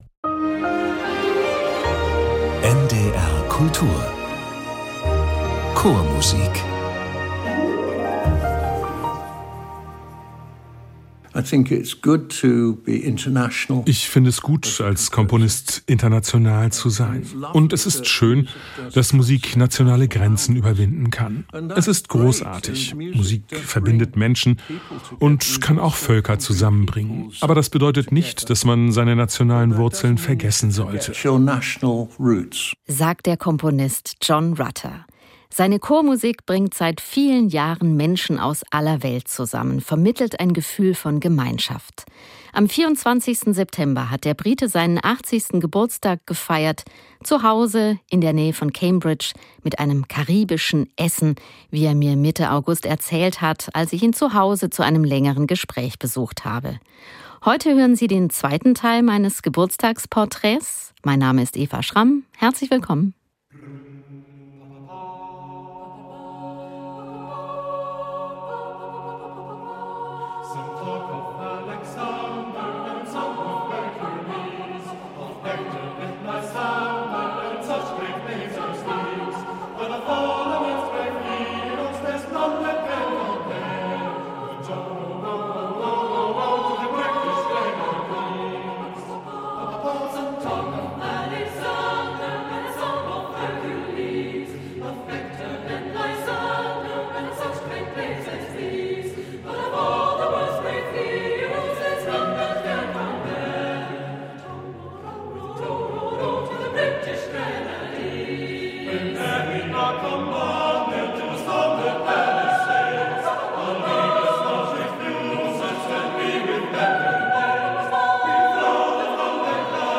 Ein Hausbesuch mit Gesprächen über die Liebe zur Chormusik, entscheidende Lebensstationen und die aktuelle Weltlage.